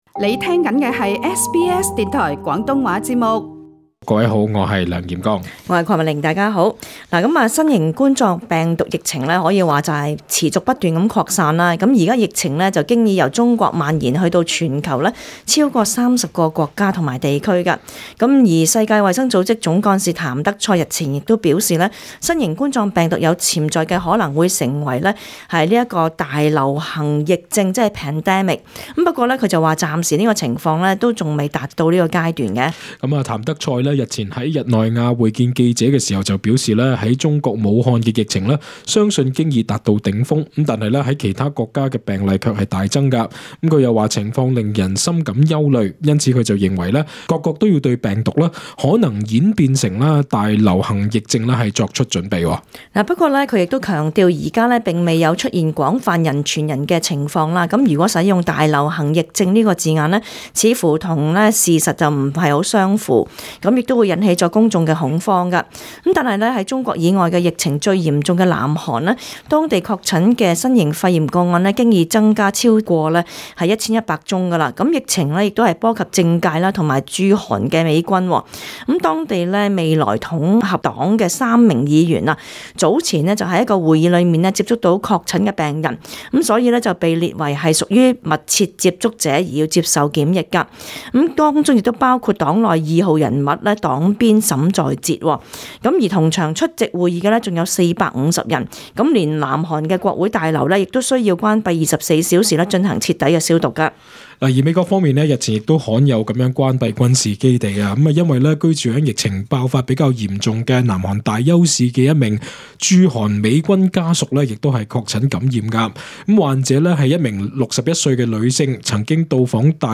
本節目內嘉賓及聽眾言論不代表本台立場 READ MORE 【新冠肺炎】澳洲政府已啓動緊急應對計劃 澳洲接近爆發『大流行』疫症邊緣？